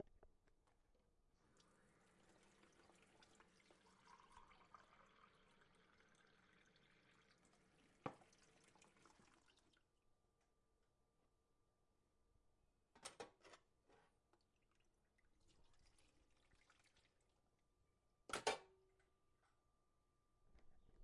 将水放进水槽并排走
描述：打开水龙头，将一些水倒入水槽。关上水龙头，等待排水。
标签： 厨房 动作 水槽 跑步 菜肴 丝锥 漏极 水龙头
声道立体声